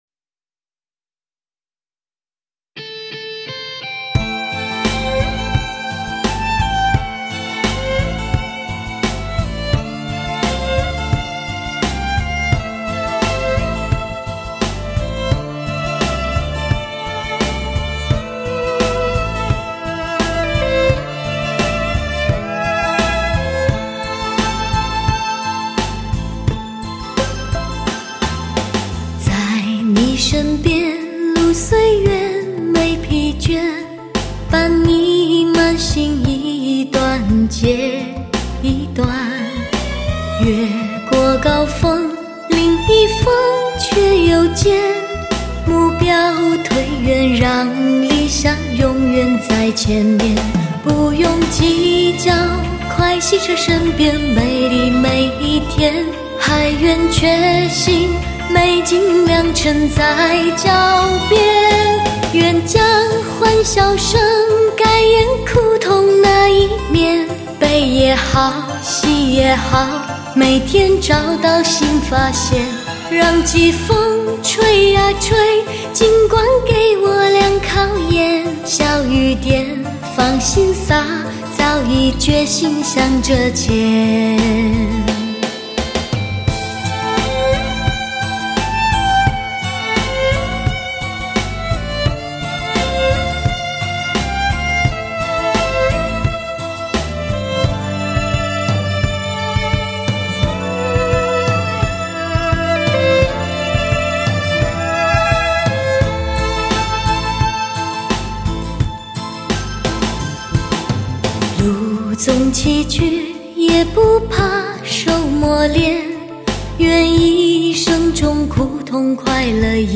极致完美的典范女声，
真正上乘的HI-FI情歌，王牌之作！